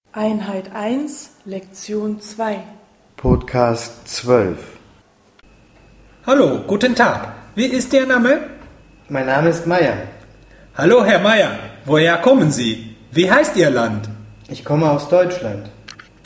Dialog 1